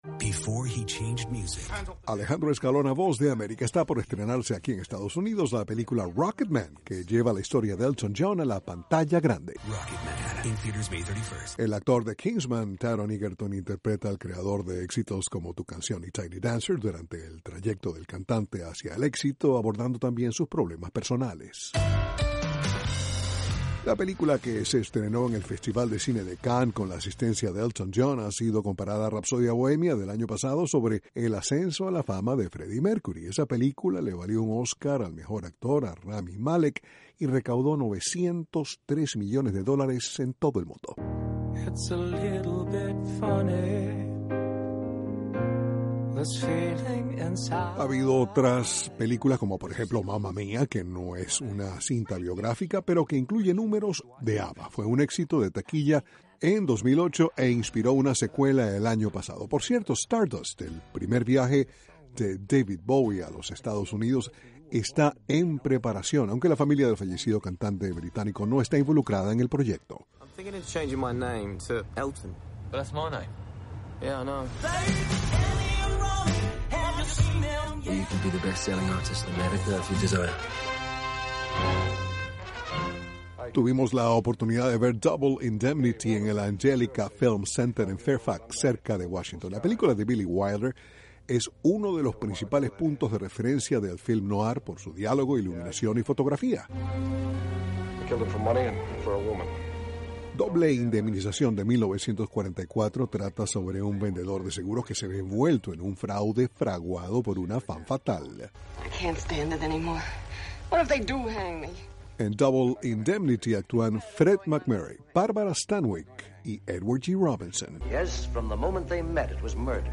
informa desde Washington...